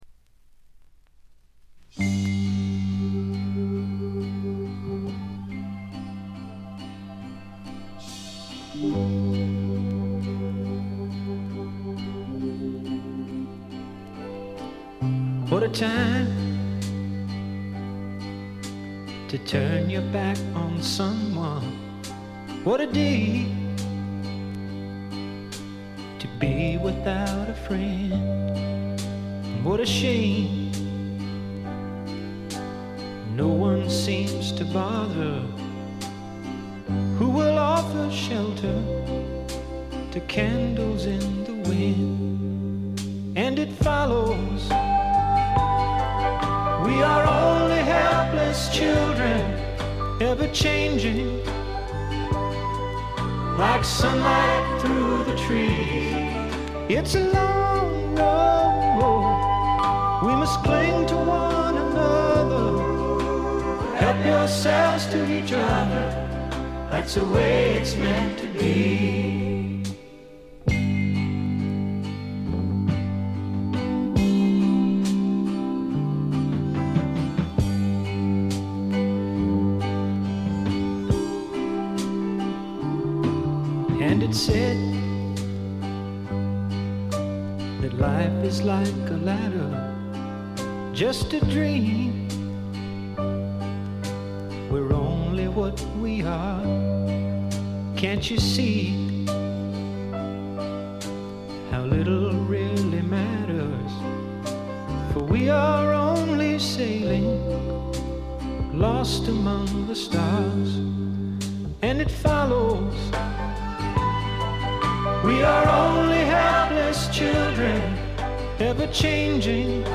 B5頭プツ音1回、他にも2回ほど散発的なプツ音。
アーシーなシンガー・ソングライターがお好きな方ならば文句なしでしょう。
試聴曲は現品からの取り込み音源です。